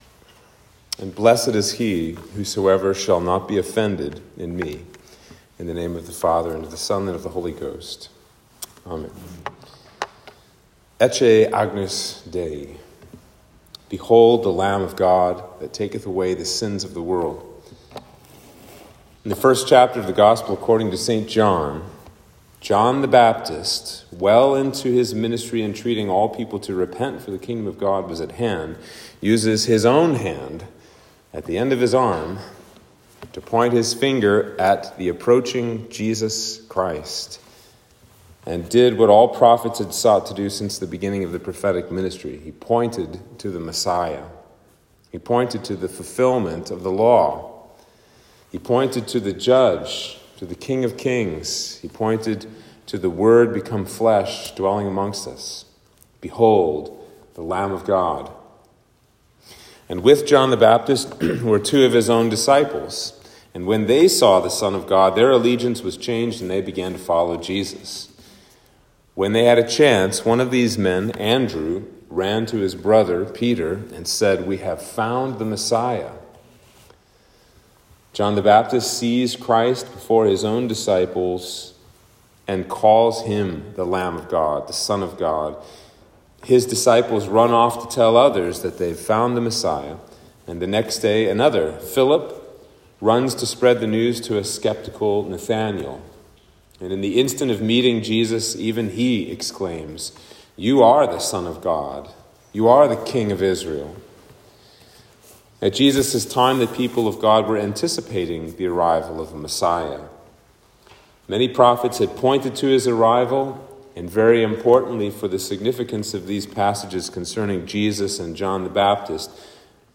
Sermon for Advent 3